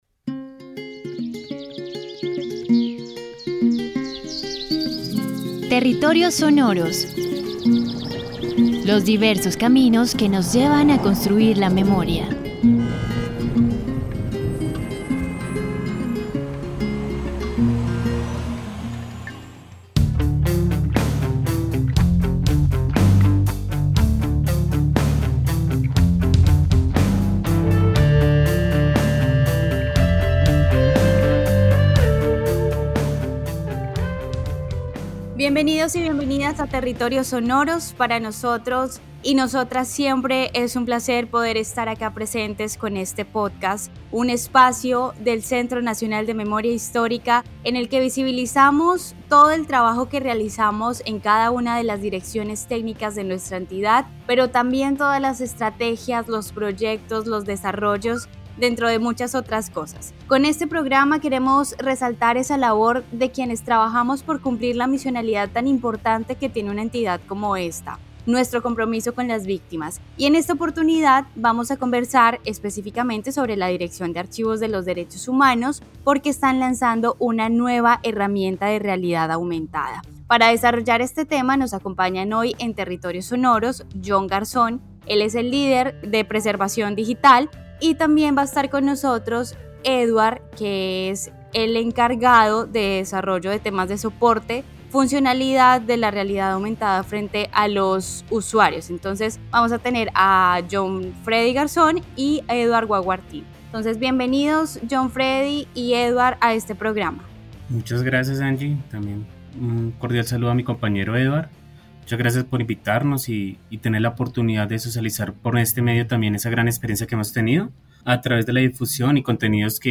En Territorios Sonoros hablamos con tres de las personas que han hecho posible estos desarrollos tecnológicos
Charla sobre la Dirección de Archivo de los Derechos Humanos del Centro Nacional de Memoria Histórica.